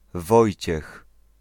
Polish: [ˈvɔjt͡ɕɛx]
Pl-Wojciech.ogg.mp3